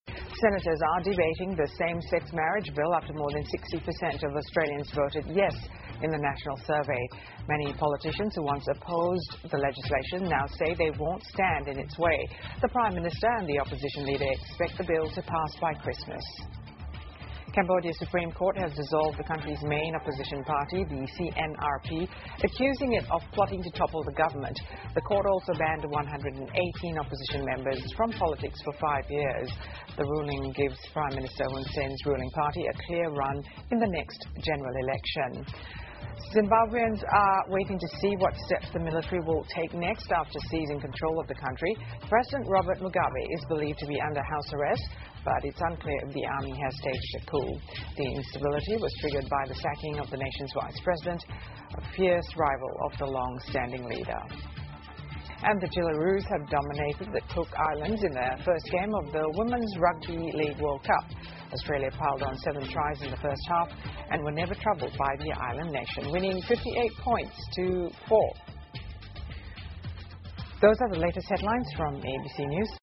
澳洲新闻 (ABC新闻快递) 澳多数选民赞同同性婚姻合法化 柬埔寨最高法院裁决解散主要反对党 听力文件下载—在线英语听力室